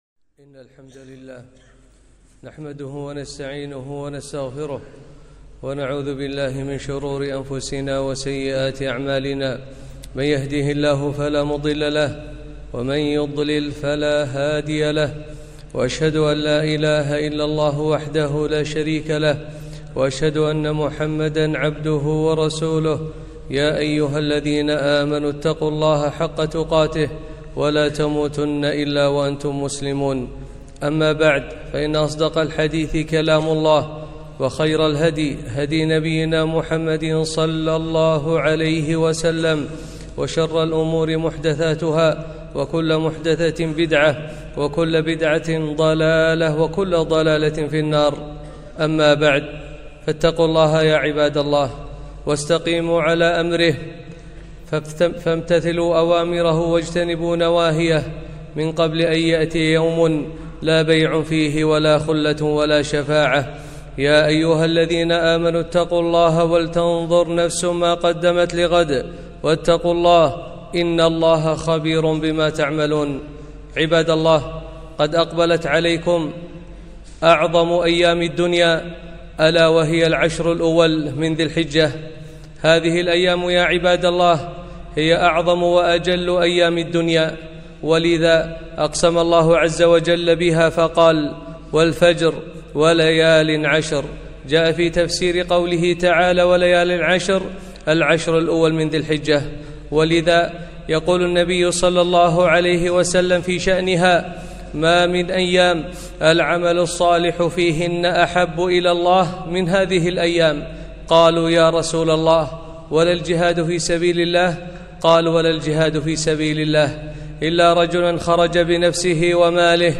خطبة - فضل عشر ذي الحجة - دروس الكويت